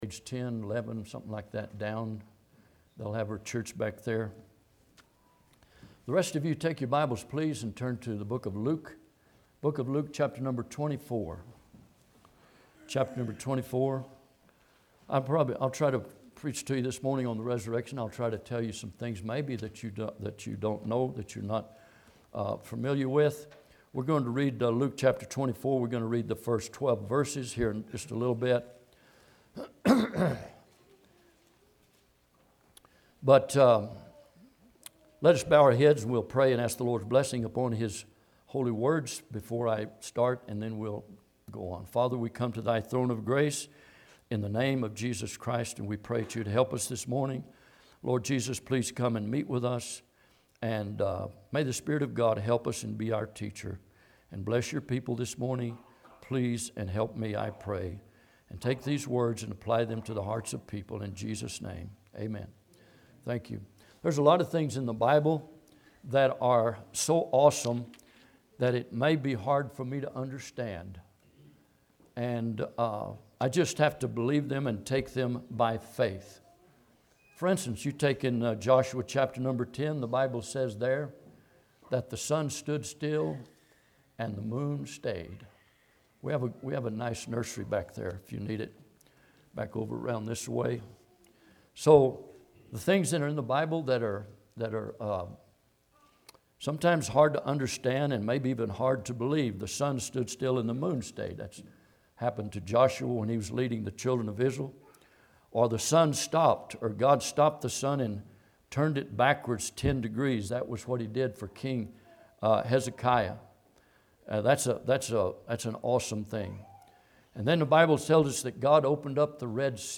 The Resurrection - Grace Fellowship Baptist Church